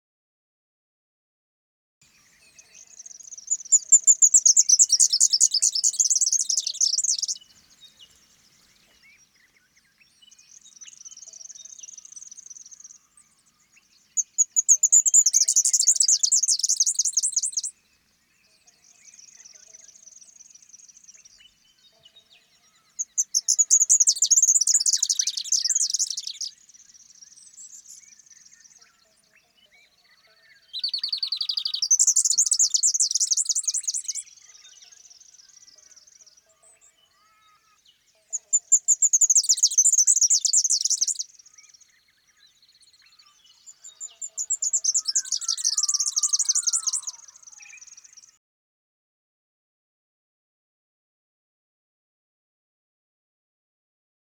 Unique Australian Bird Sounds
superb fairy wren
17-superb-fairy-wren.mp3